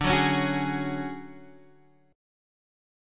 DM#11 chord